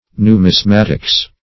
Numismatics \Nu`mis*mat"ics\, n. [Cf. F. numismatique.]